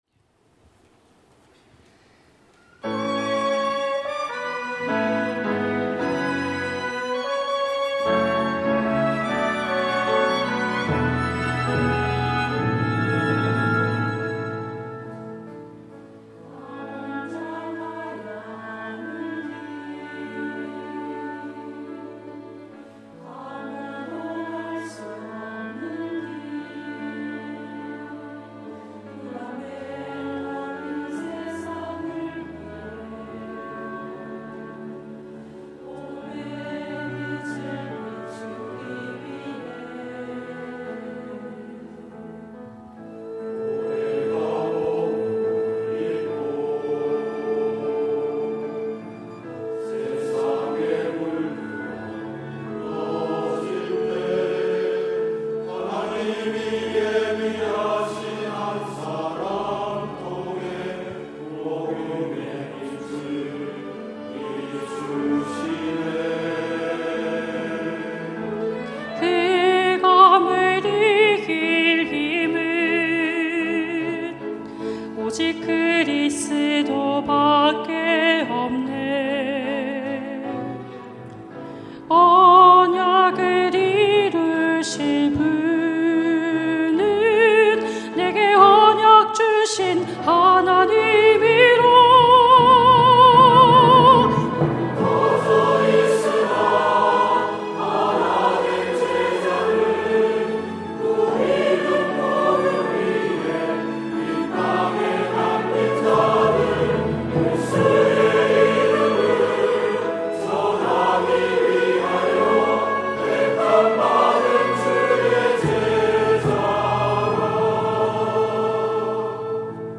온세대예배 찬양대